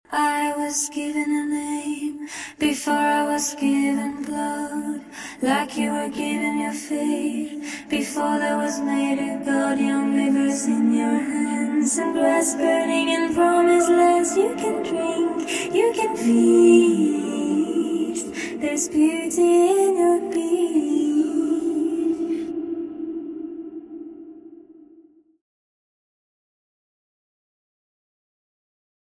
vocals only